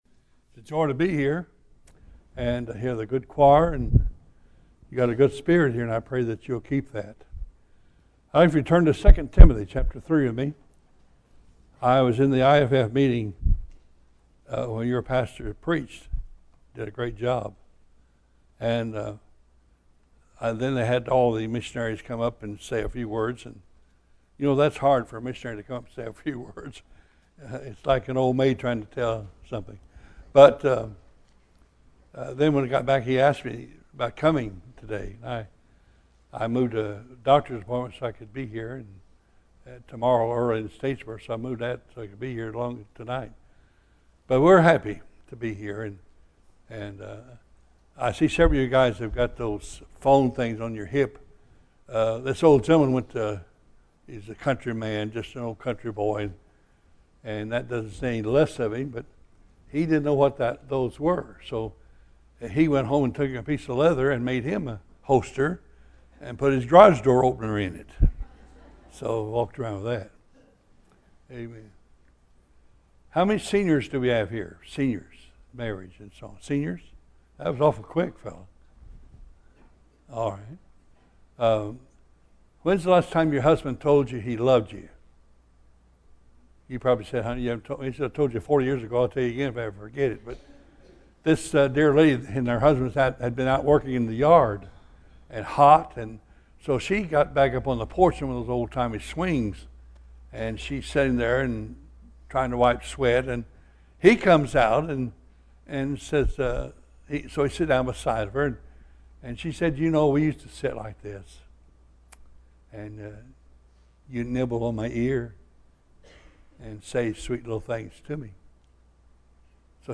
Bible Text: II Timothy 3 | Preacher